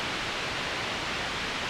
normal-sliderslide.ogg